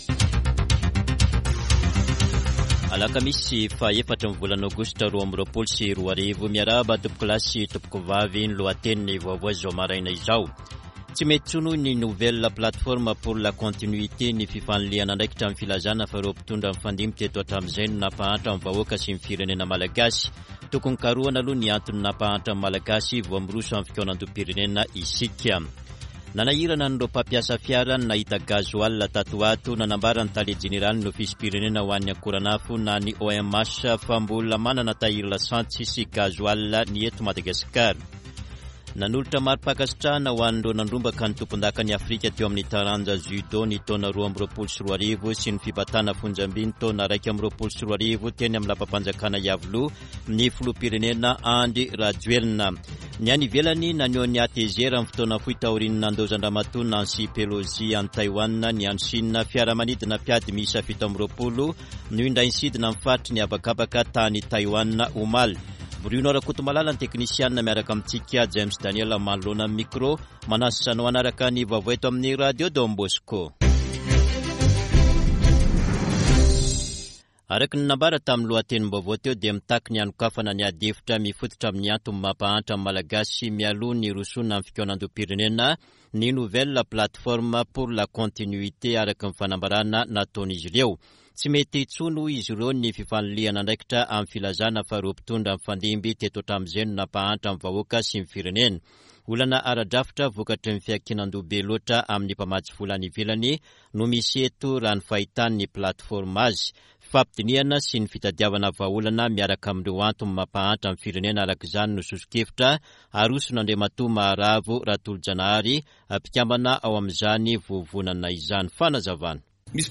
[Vaovao maraina] Alakamisy 04 aogositra 2022